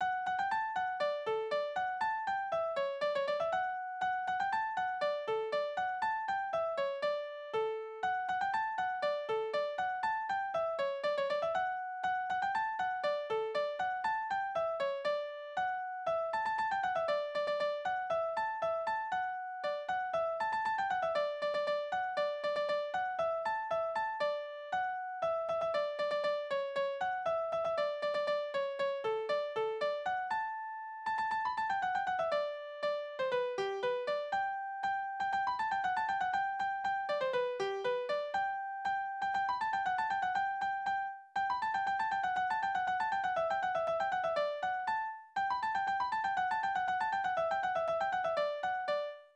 « 10898 » Kastilianer Tanzverse Tonart: D-Dur Taktart: 2/4 Tonumfang: große Dezime Externe Links